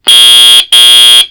Play, download and share FTC auto complete original sound button!!!!
autonomous_complete.mp3